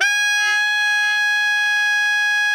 ALTO  FF G#4.wav